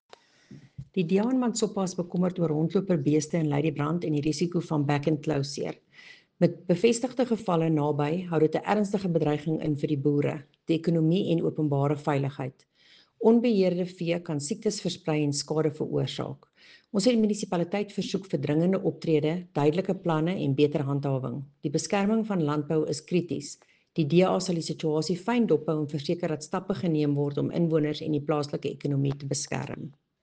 Afrikaans soundbites by Cllr Erica Moir and